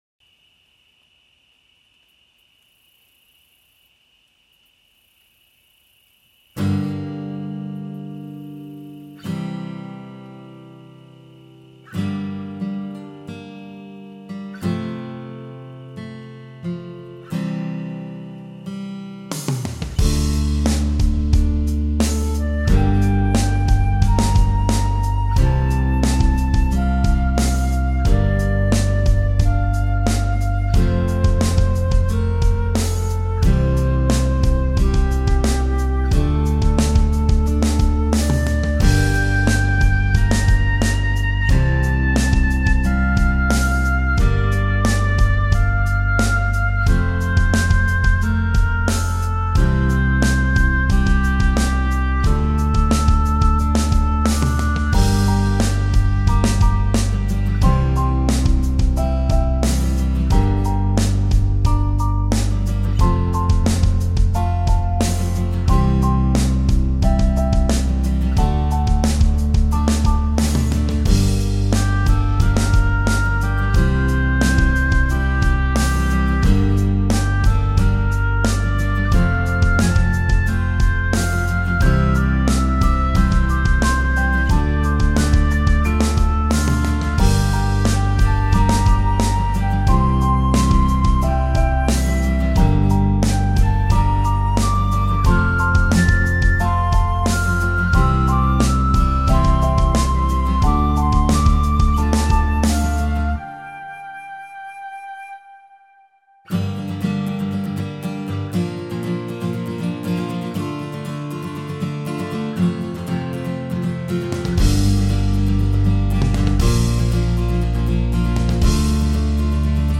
genre:light rock